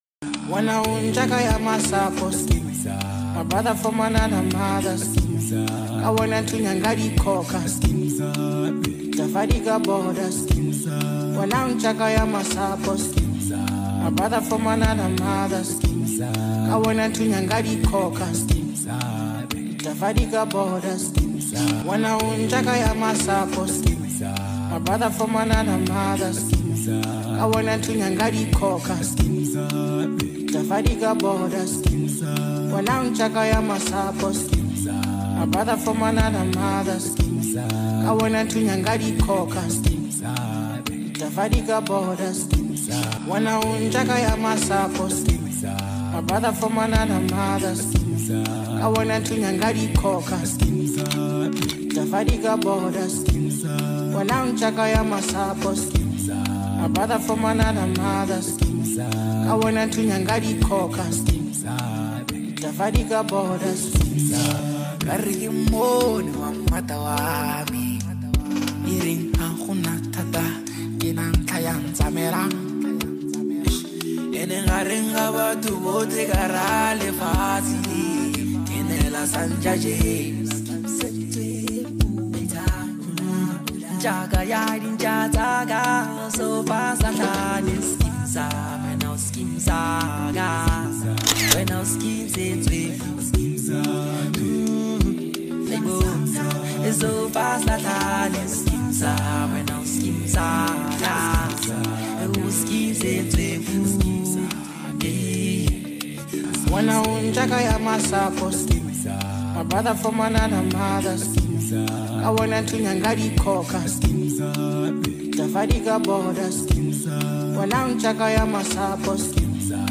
The song is a mix of great beats